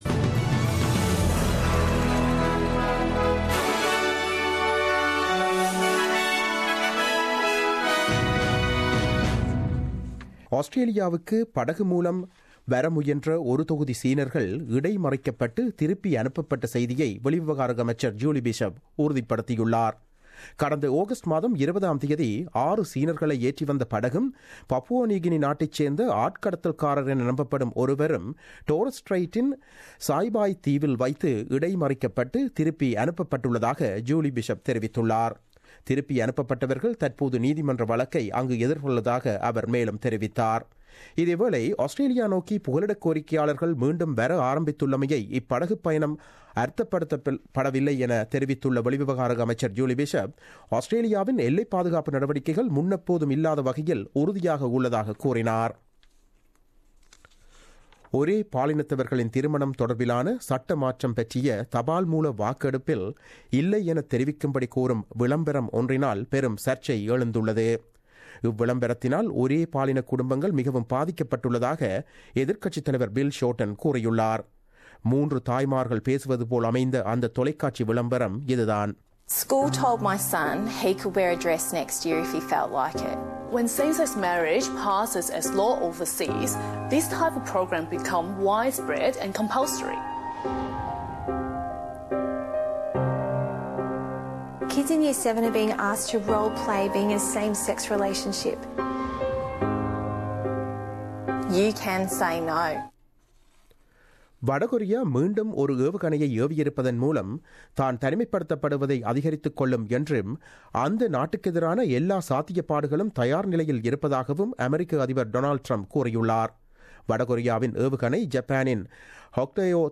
The news bulletin broadcasted on 30 August 2017 at 8pm.